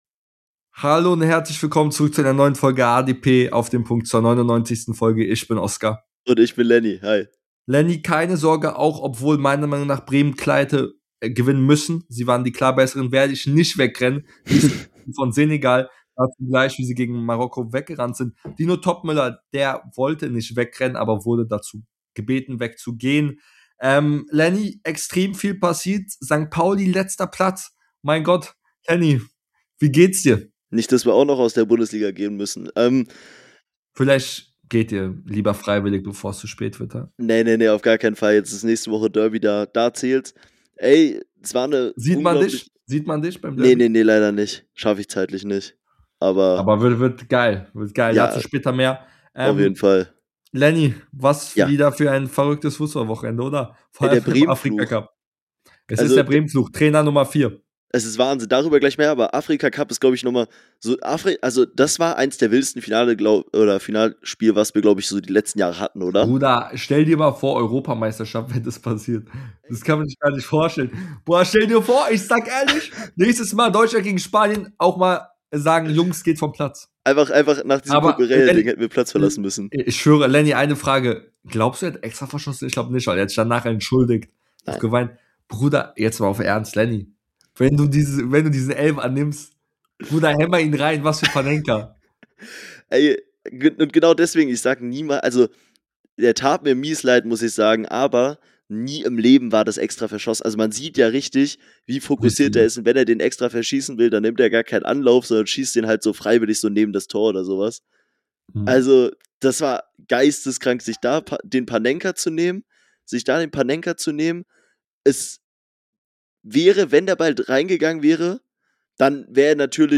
In der heutigen Folge sprechen die beiden Hosts über das turbulente Africa Cup Finale , Toppmöllers Entlassung , kriselnde Mainzer und vieles mehr